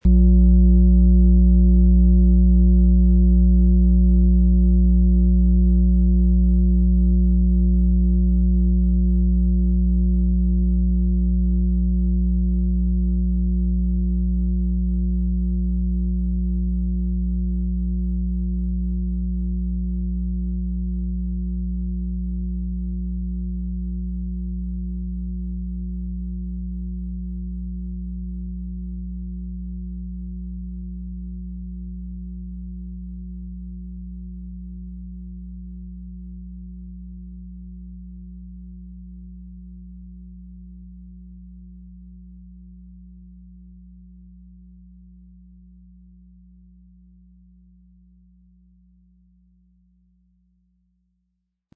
Planetenton 1
• Tiefgründige Schwingungen: Sanft und durchdringend – sie berühren dein Innerstes.